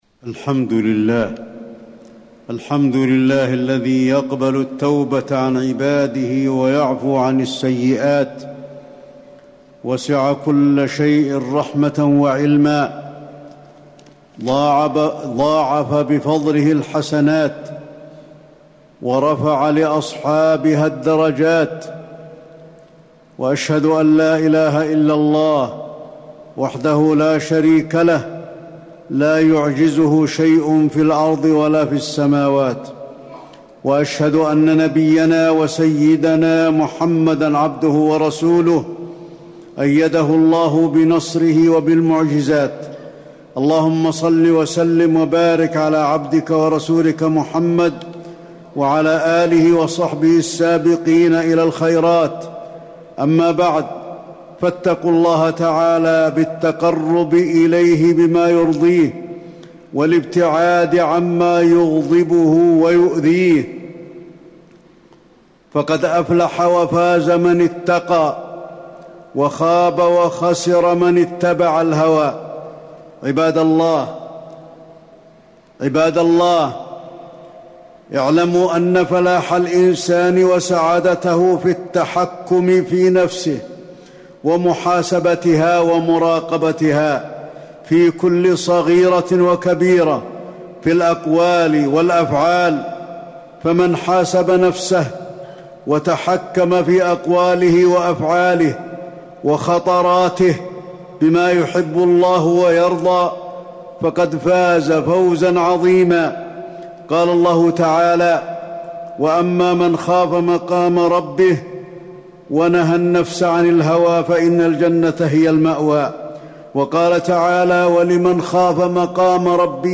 تاريخ النشر ٢١ ذو القعدة ١٤٣٩ هـ المكان: المسجد النبوي الشيخ: فضيلة الشيخ د. علي بن عبدالرحمن الحذيفي فضيلة الشيخ د. علي بن عبدالرحمن الحذيفي محاسبة النفس